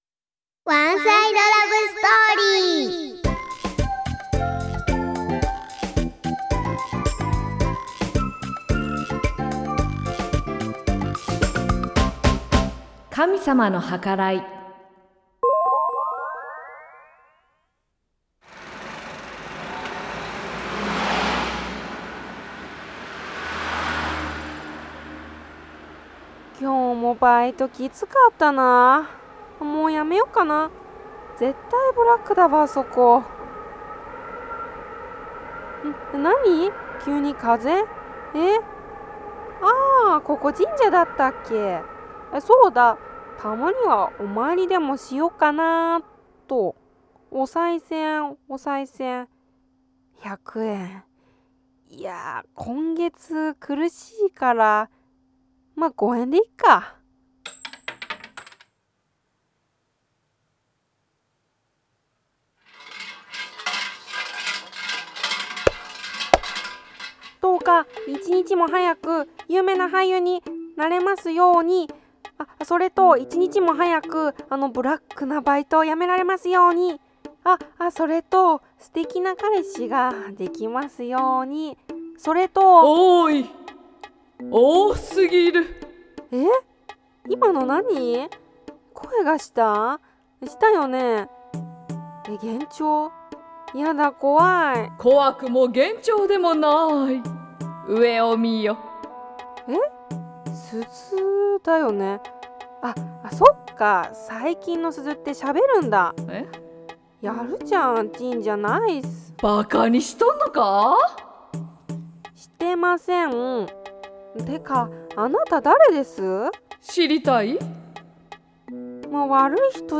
そこはいつもの通り道にある神社の境内 突然風が吹き荒れ、 バイト終わりの疲れた私を不思議な声が呼び止めた・・・